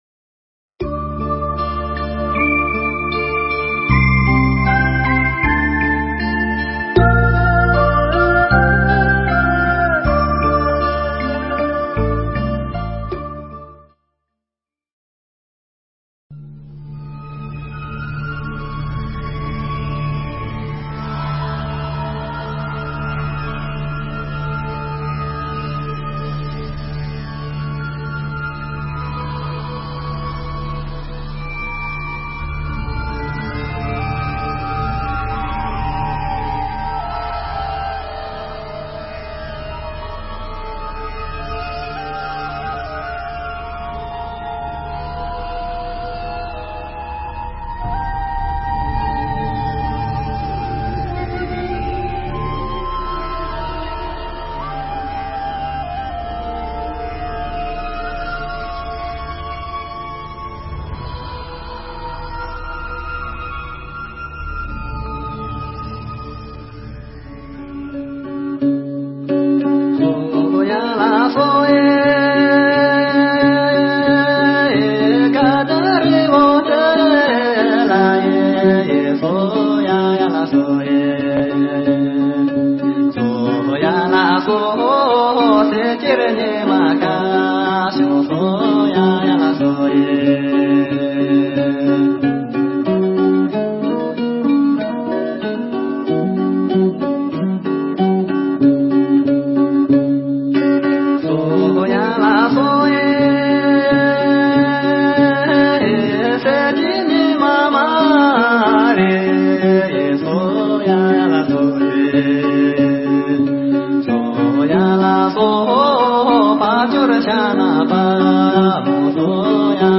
Nghe Mp3 thuyết pháp Cao Nguyên Thánh Địa